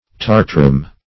tartarum - definition of tartarum - synonyms, pronunciation, spelling from Free Dictionary Search Result for " tartarum" : The Collaborative International Dictionary of English v.0.48: Tartarum \Tar"ta*rum\, n. (Chem.)